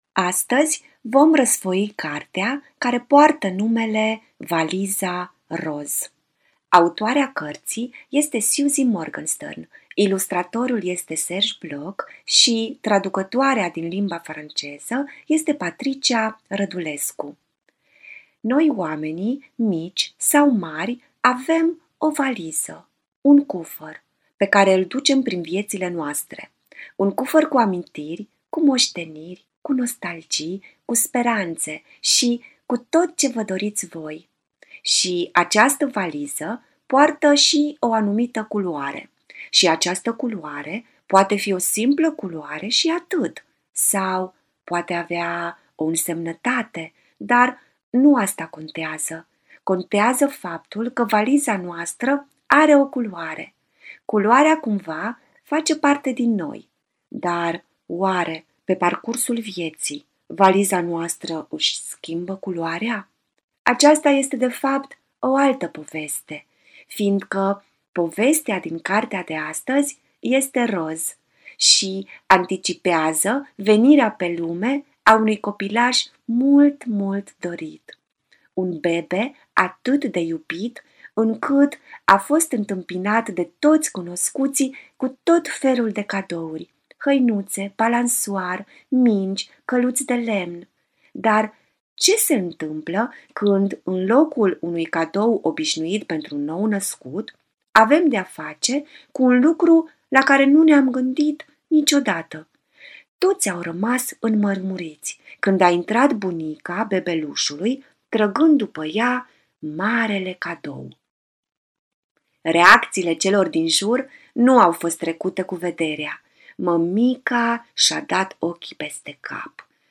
artist păpușar